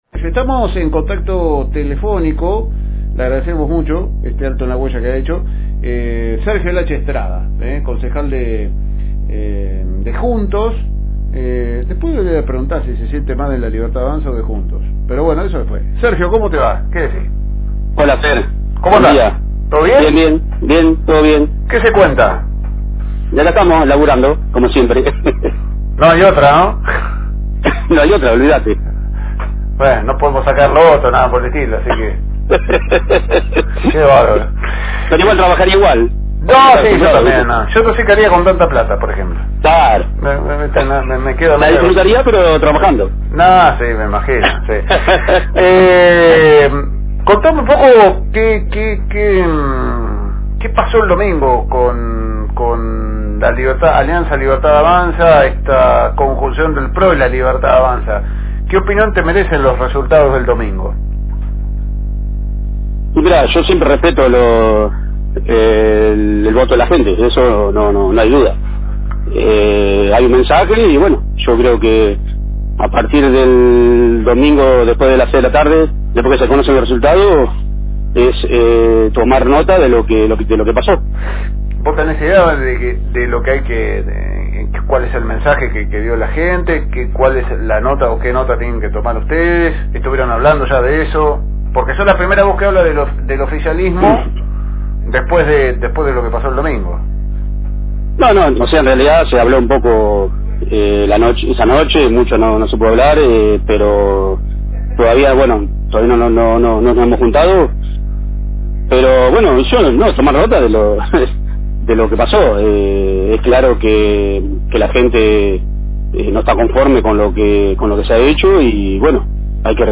Compartimos la entrevista con el concejal Sergio «Hache» Estrada perteneciente al espacio político JUNTOS, que en esta última elección realizó alianza estratégica con La Libertad Avanza.